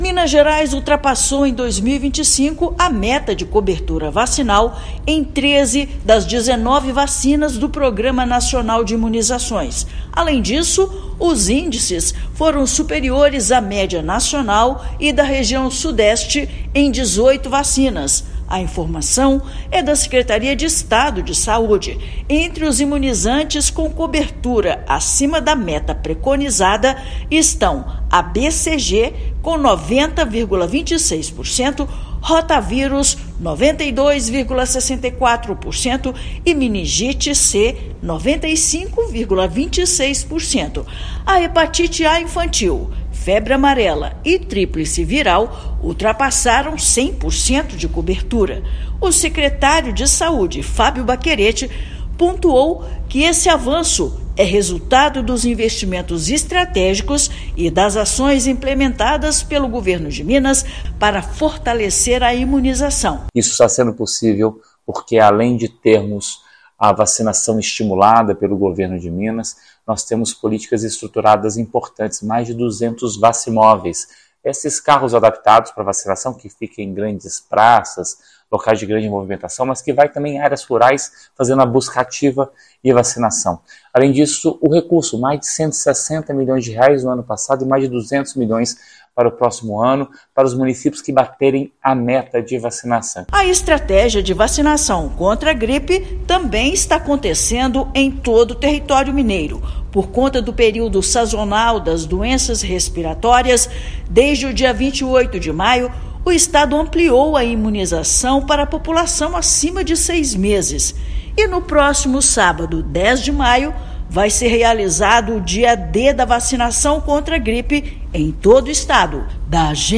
Aumento dos índices vacinais é reflexo dos investimentos feitos pelo governo estadual em ações de vacinação extramuros e campanhas de conscientização. Ouça matéria de rádio.